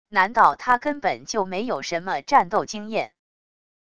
难道他根本就没有什么战斗经验wav音频生成系统WAV Audio Player